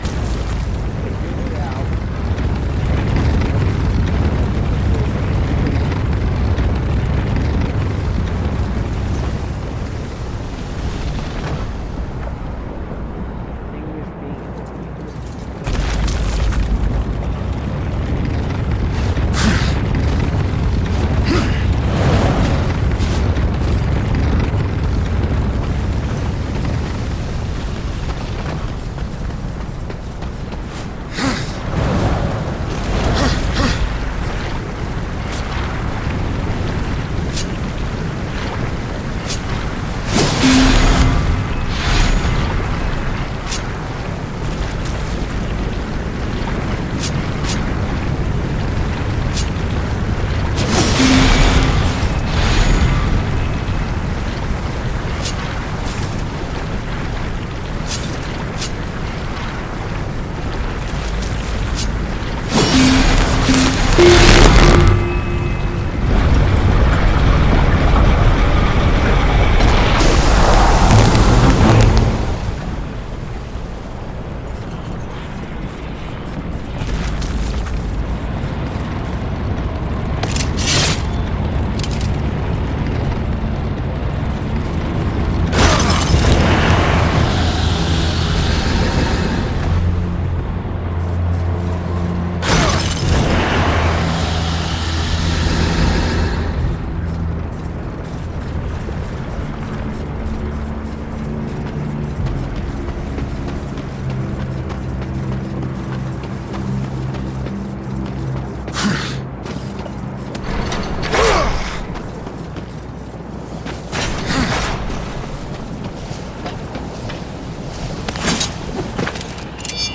I play Darksiders with commentary